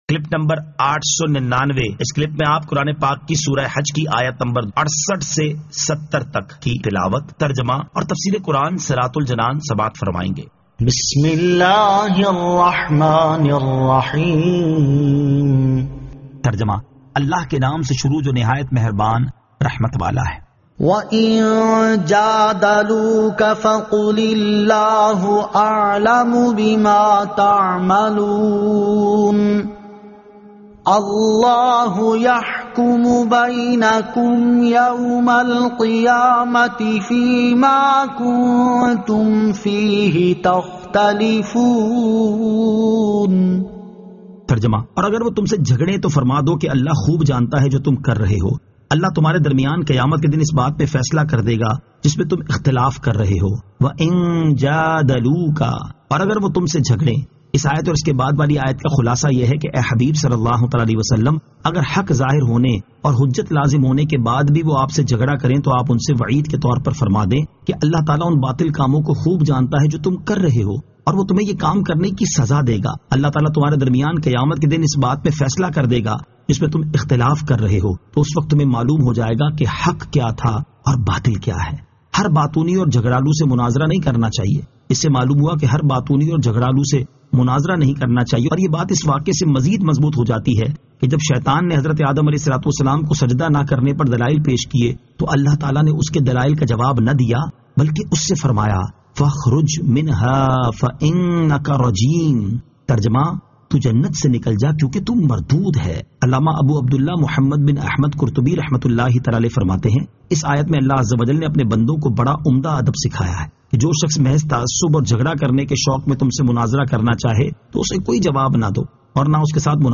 Surah Al-Hajj 68 To 70 Tilawat , Tarjama , Tafseer